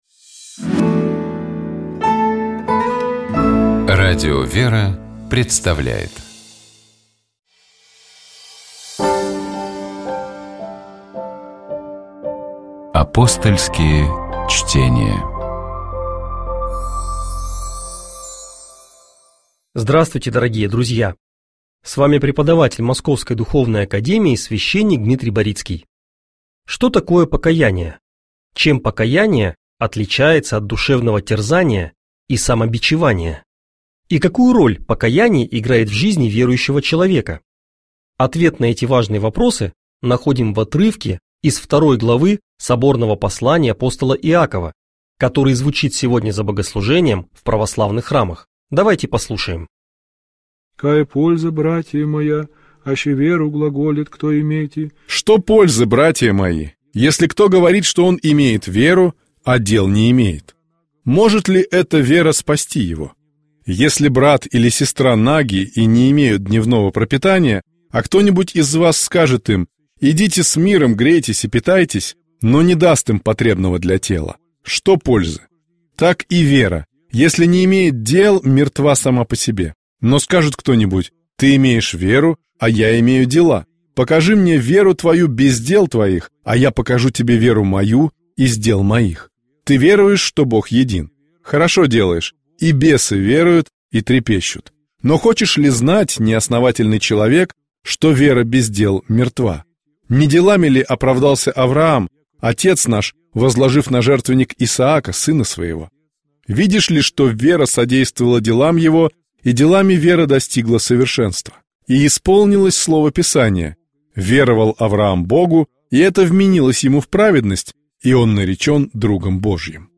Псалом 8. Богослужебные чтения - Радио ВЕРА